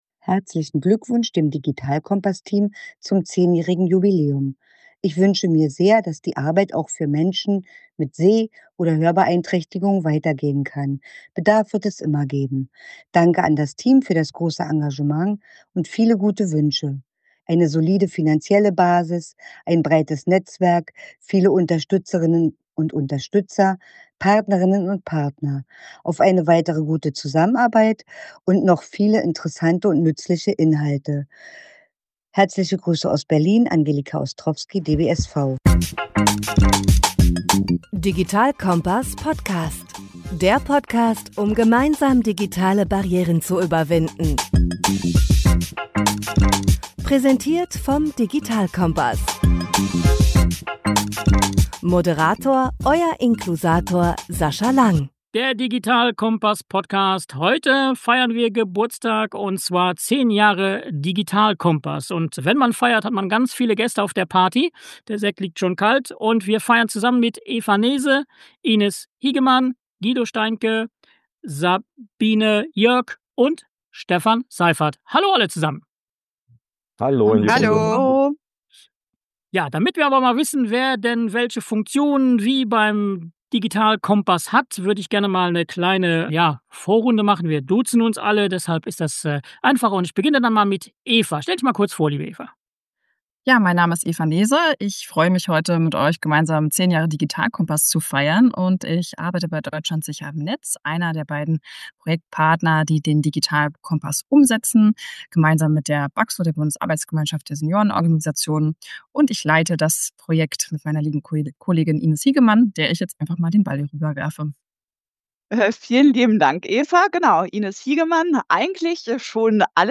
Beschreibung vor 6 Monaten 10 Jahre Digital-Kompass Ein Gespräch mit dem Team des Digital-Kompass über zehn Jahre Engagement für digitale Teilhabe.